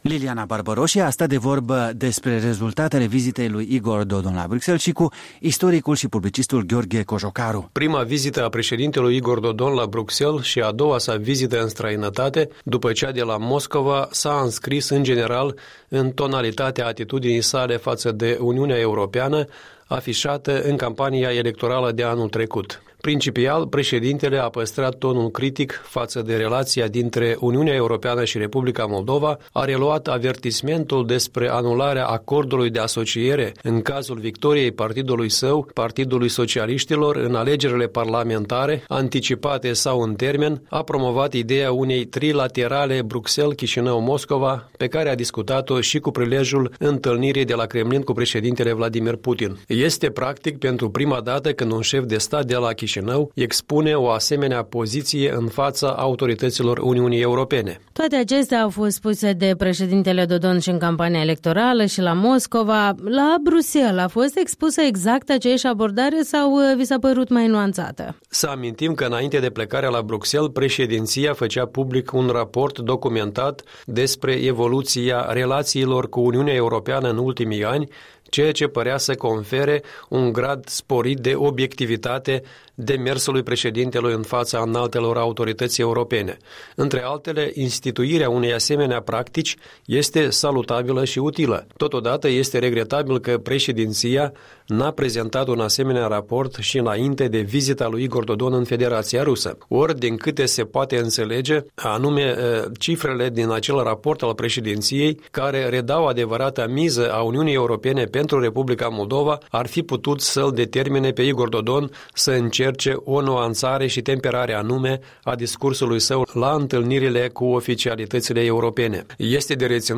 Un punct de vedere săptămînal în dialog.